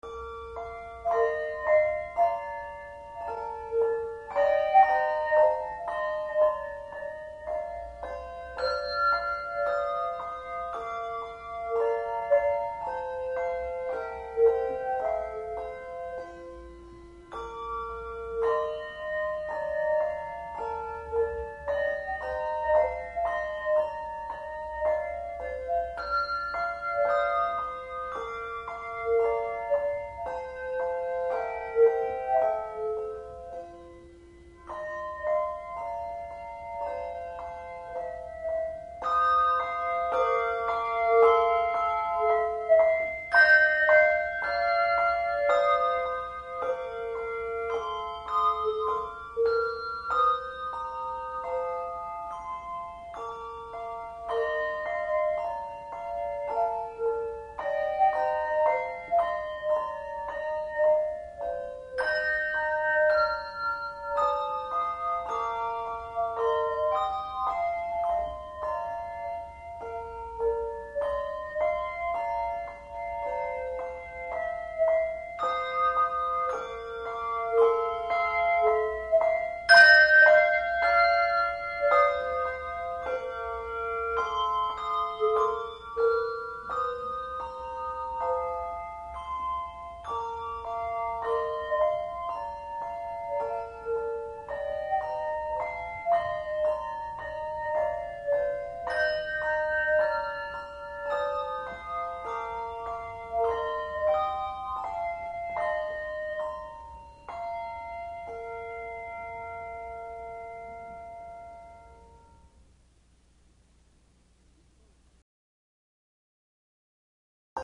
Handbell Quartet
No. Octaves 3 Octaves